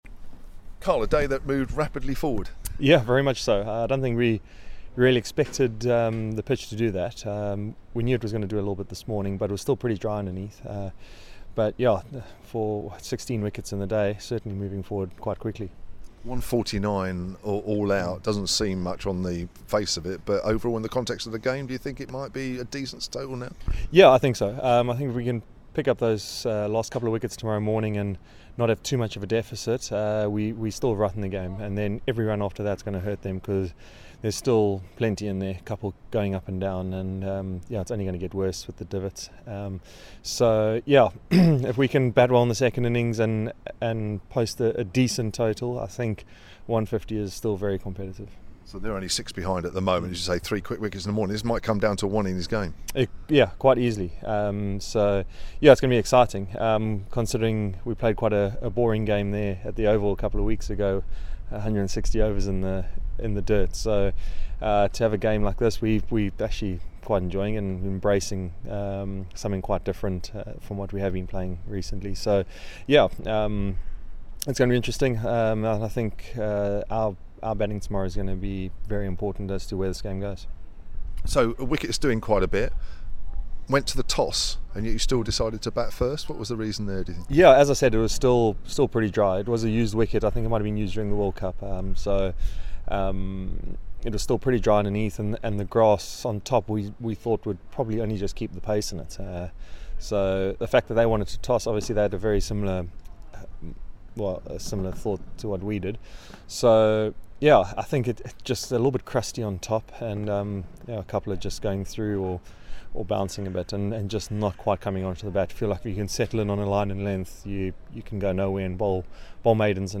KYLE ABBOTT SPEAKING AFTER DAY 1 v SURREY